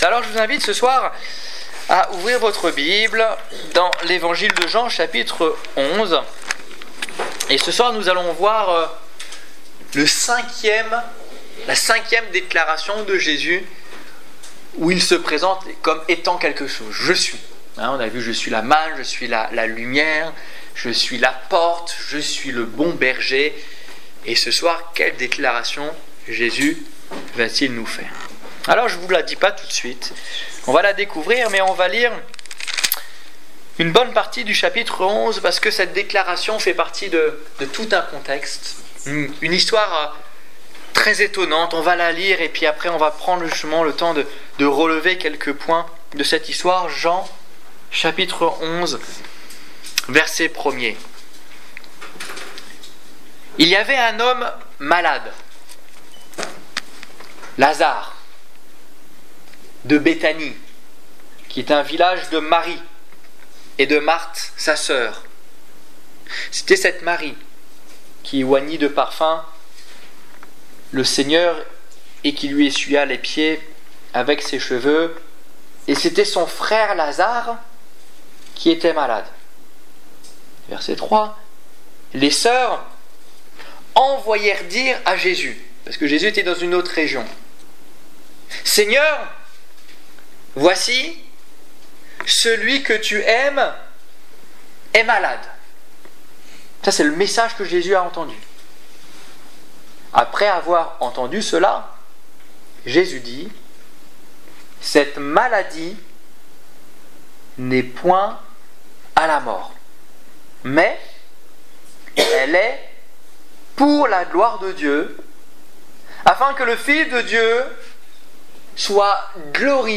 Je suis la résurrection et la vie Détails Prédications - liste complète Évangélisation du 12 juin 2015 Ecoutez l'enregistrement de ce message à l'aide du lecteur